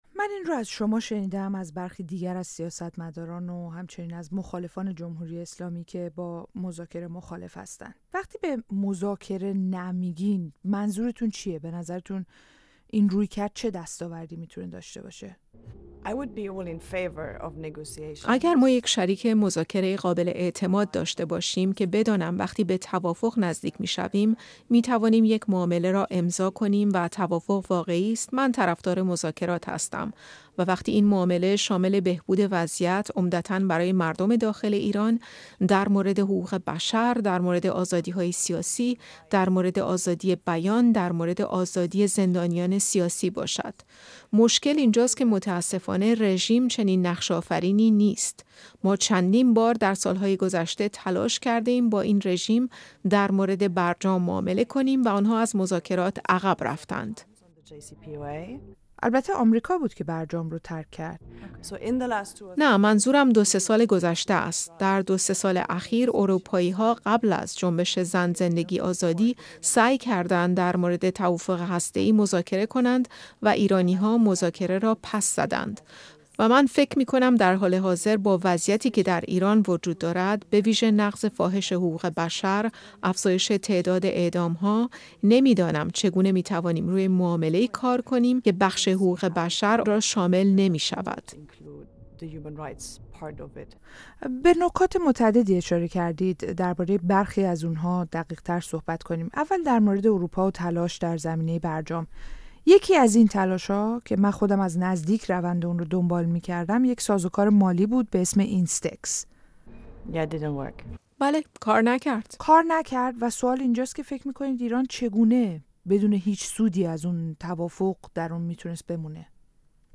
آیا کشورهای اروپایی با رویکرد آمریکا همراه می‌شوند یا راهی جدا در پیش می‌گیرند؟ گفت‌وگوی رادیوفردا با هانا نویمان، رئیس هیئت روابط با ایران در پارلمان اروپا، را بشنوید.